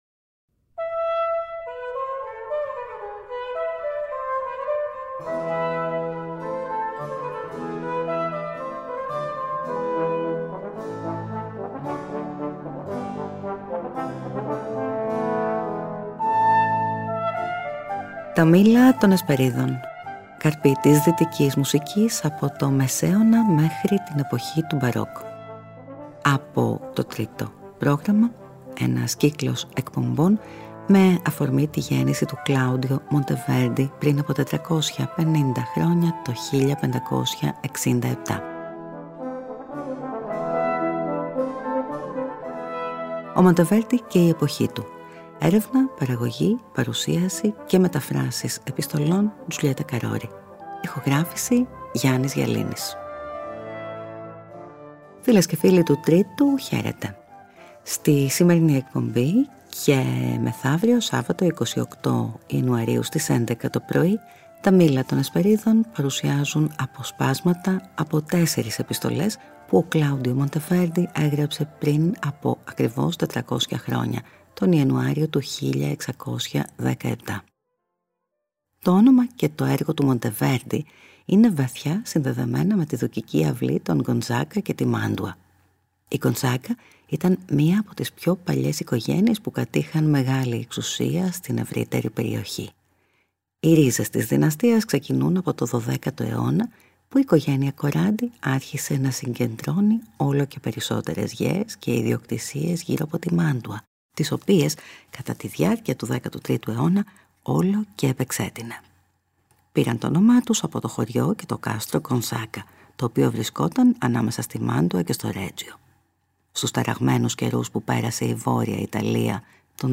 Στην ίδια εκπομπή αποσπάσματα από τον Ορφέα, μαδριγάλια από το δεύτερο βιβλίο του και συνθέσεις από τη συλλογή Selva morale e spirituale.